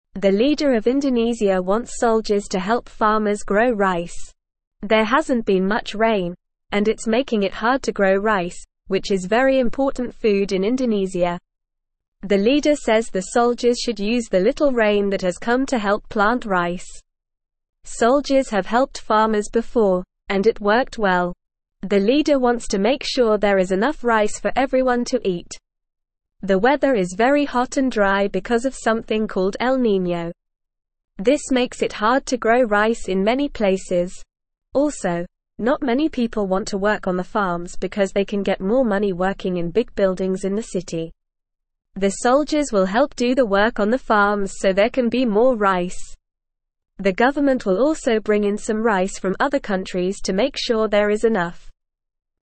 English-Newsroom-Beginner-NORMAL-Reading-Soldiers-to-Help-Farmers-Grow-Rice-in-Indonesia.mp3